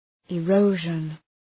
Προφορά
{ı’rəʋʒən}